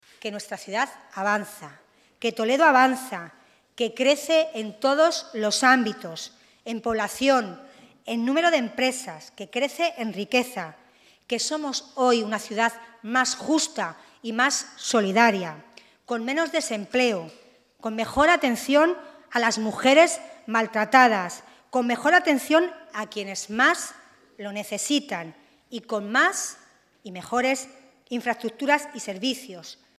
La alcaldesa de Toledo, Milagros Tolón, ha hecho repaso a los principales indicadores socioeconómicos de la ciudad a propósito de la celebración del tercer Debate del Estado de la Ciudad en la presente legislatura, una ocasión en la que la primera edil ha mantenido que “Toledo avanza, crece en población, en número de empresas y en riqueza, tiene menos desempleo y una hacienda municipal saneada, se encuentra mejor que hace tres años y puede mirar al futuro con esperanza y optimismo”.
Audio Milagros Tolón: